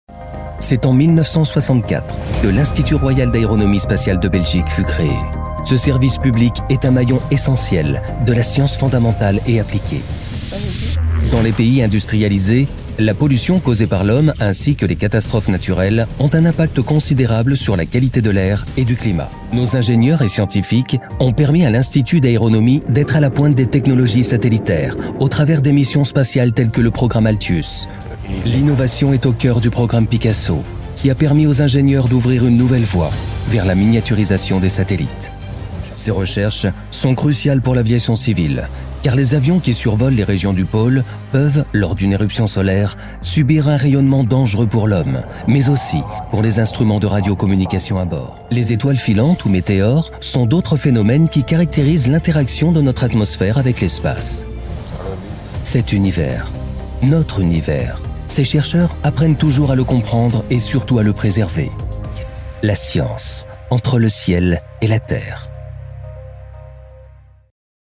Démos Documentaires
Les démos sur cette page sont des extraits de projets réels livrés et mixés par les studios respectifs.